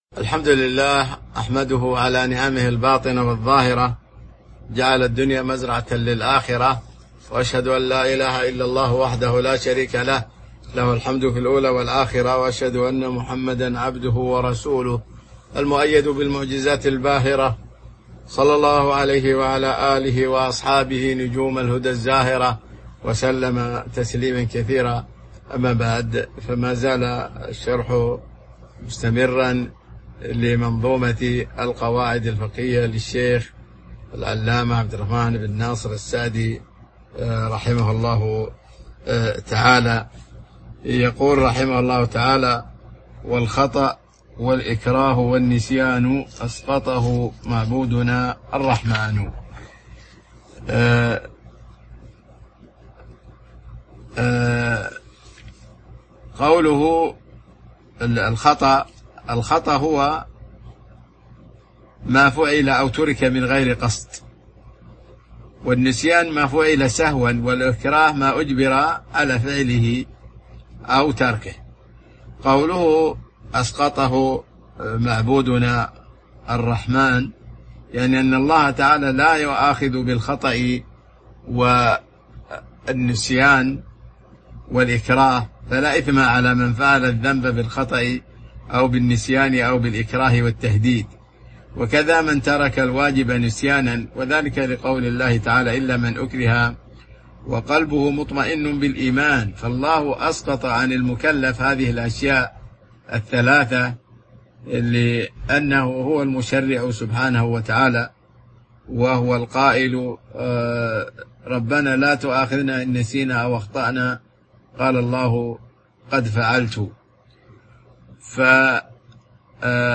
تاريخ النشر ١ ذو القعدة ١٤٤٢ هـ المكان: المسجد النبوي الشيخ